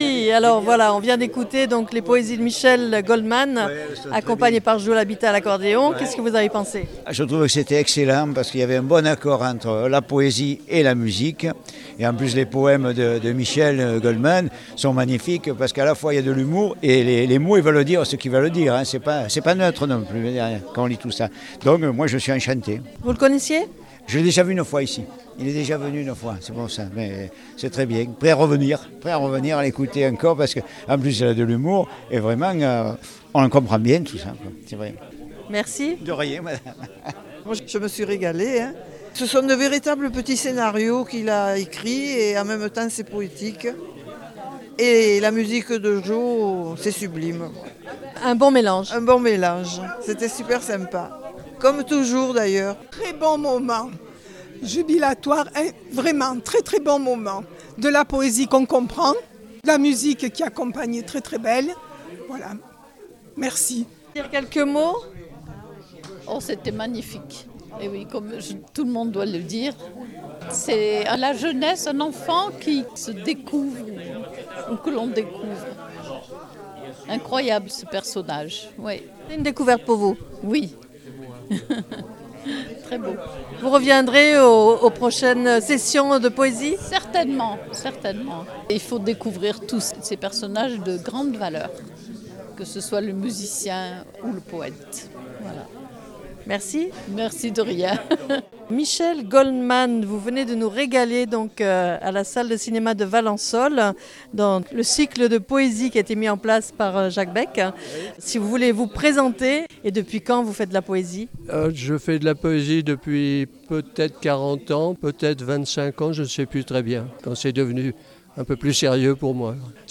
à l'accordéon
accordéoniste
tout d'abord les réactions du public